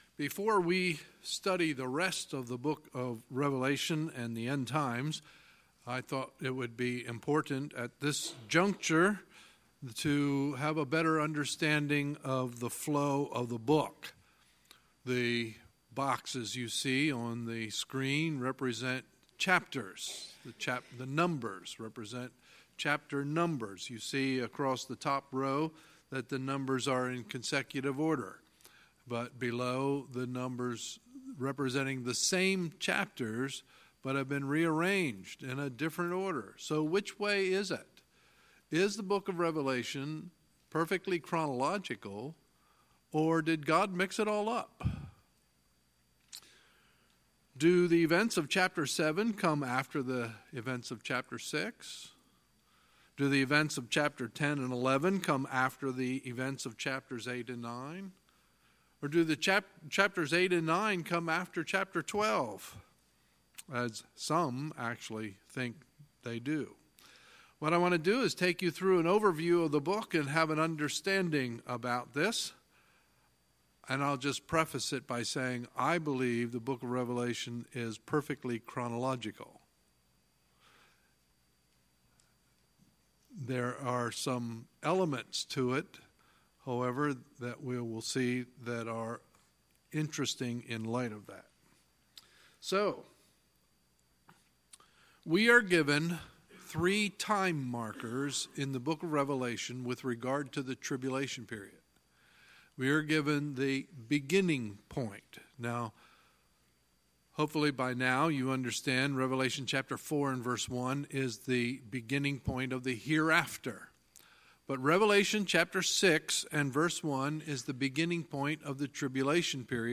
Sunday, December 9, 2018 – Sunday Evening Service